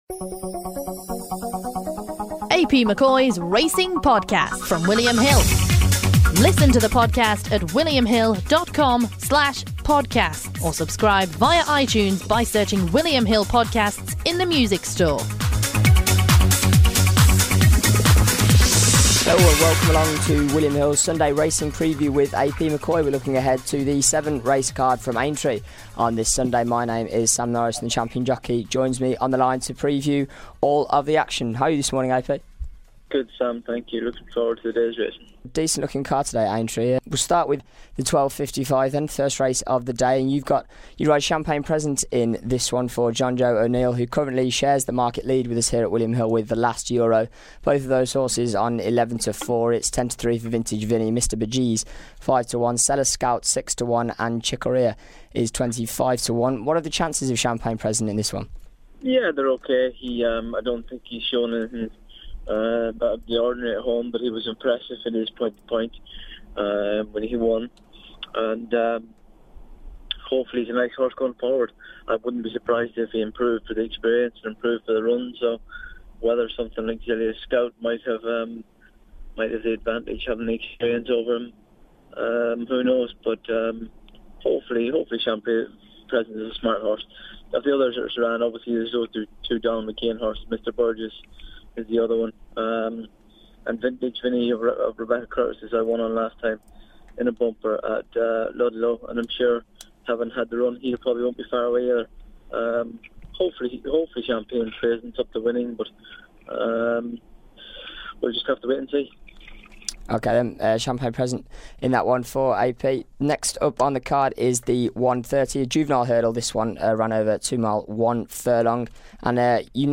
Aintree takes the focus of today's preview as we look forward to the seven race card, with champion jockey AP McCoy offering his thoughts.